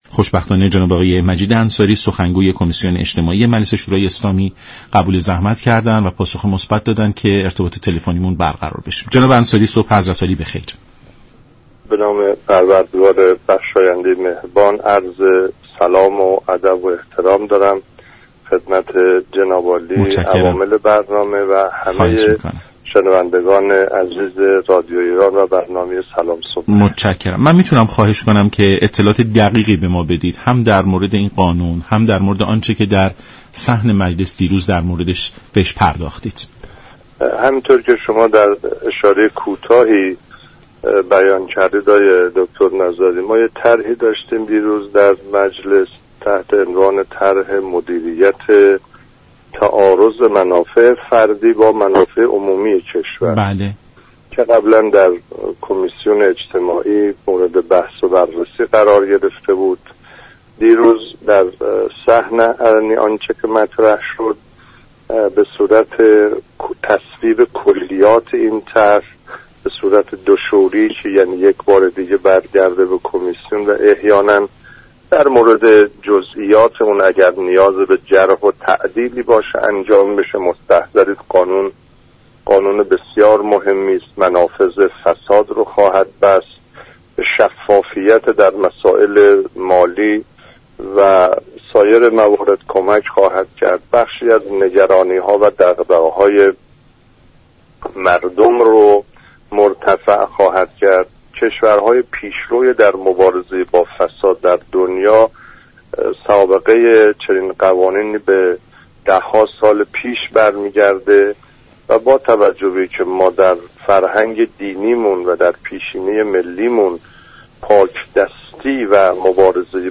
دریافت فایل شخصیت مهم خبری: مجید انصاری سخنگوی كمیسیون اجتماعی مجلس شورای اسلامی تنظیم كننده